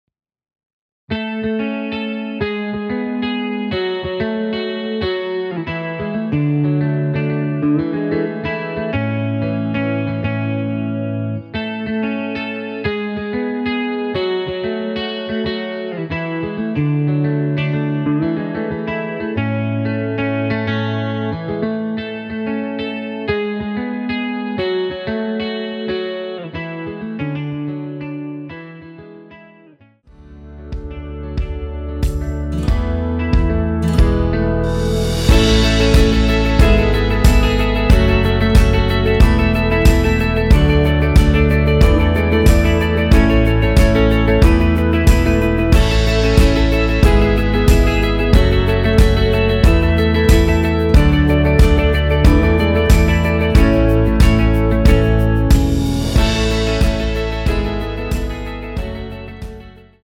원키 MR입니다.
앞부분30초, 뒷부분30초씩 편집해서 올려 드리고 있습니다.
중간에 음이 끈어지고 다시 나오는 이유는